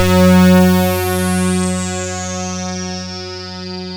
KORG F#3 1.wav